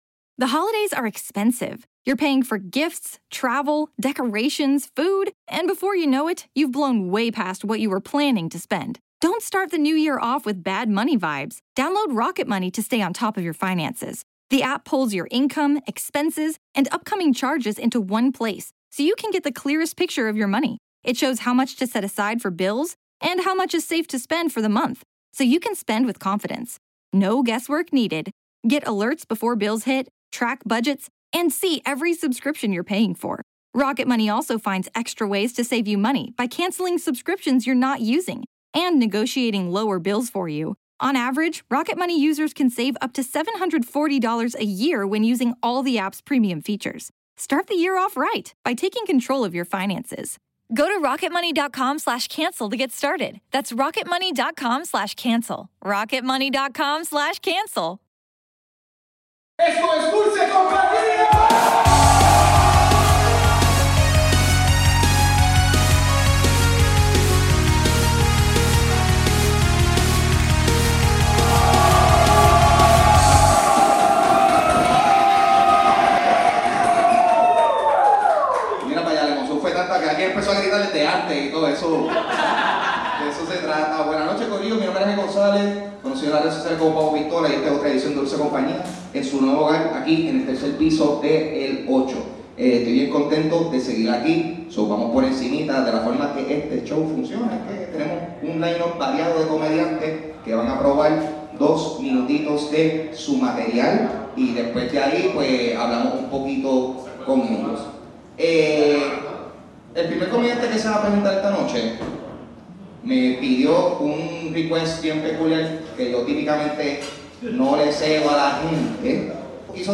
Otra noche de comedia en Rio Piedras